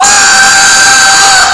僵尸
Tag: 怪物 恐怖 僵尸